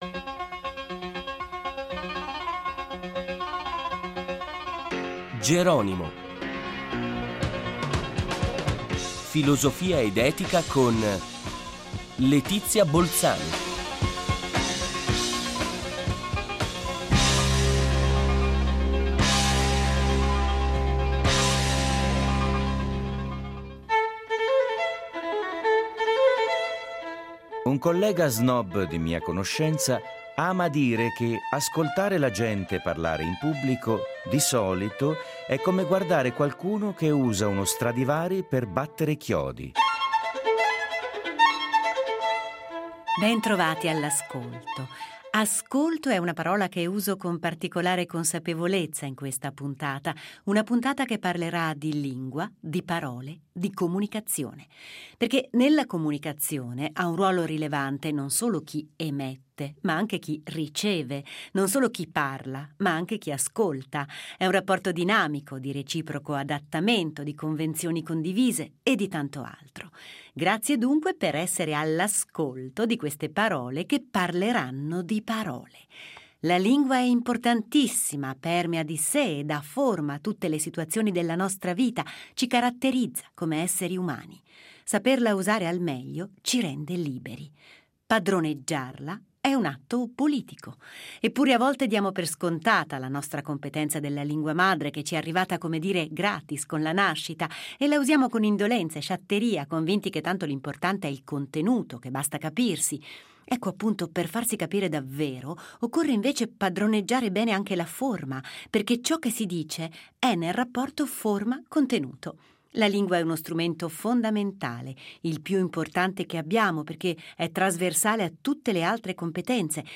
Padroneggiare la lingua vuol dire essere cittadini liberi, è un atto politico e sociale. L’ospite di questa puntata è una sociolinguista